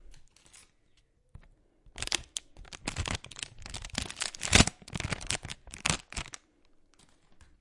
皱皱巴巴的脆皮 " 铝箔脆皮
描述：铝箔冲击碾压。立体声Tascam DR05
Tag: 褶皱 褶皱 ASMR 立体声 锡箔 铝箔